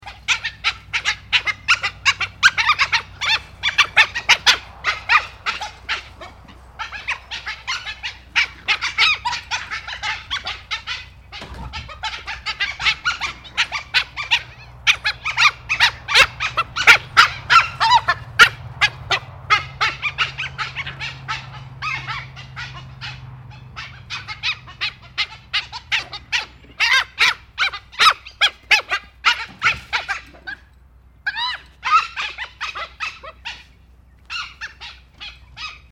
Звуки красного волка
Агрессивное вытье красного волка